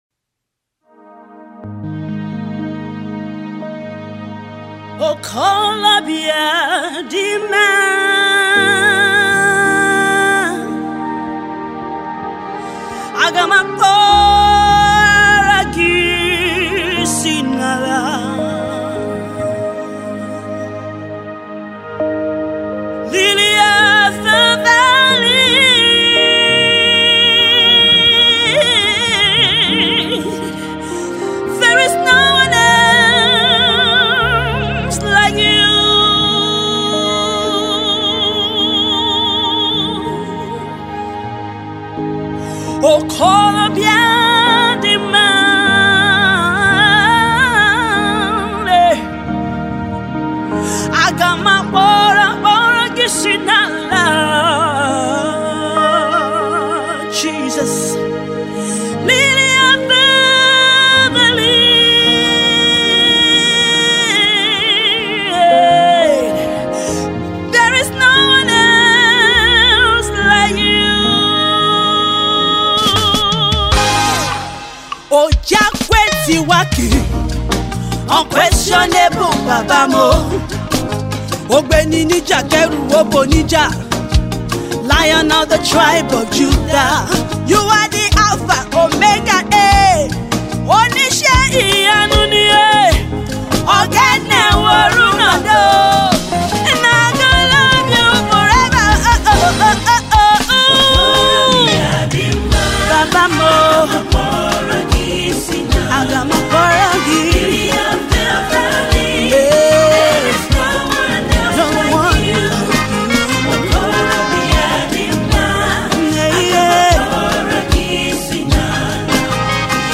introduces a brand new gospel music